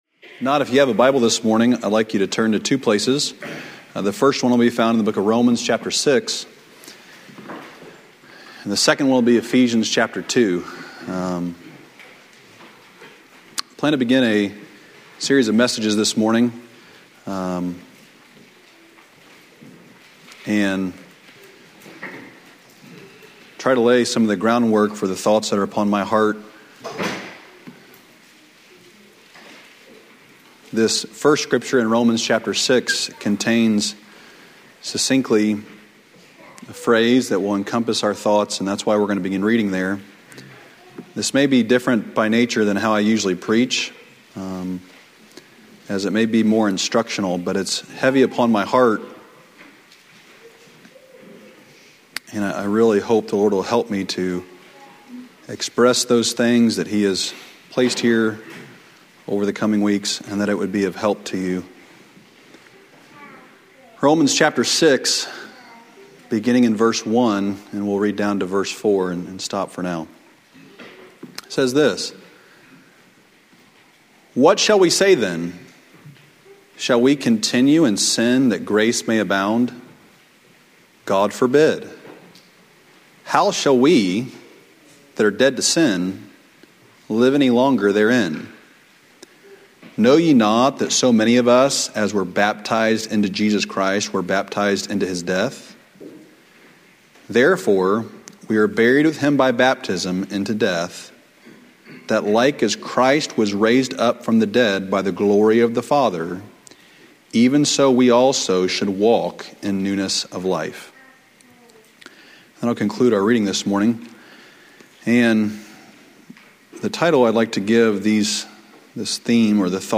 Recent Sermons|Old Union Missionary Baptist Church
Sermons from our Sunday morning worship services.